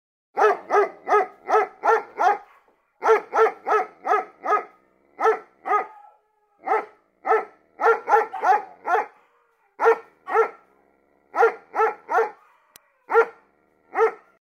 Звуки собак
Звук громкого лая крупного пса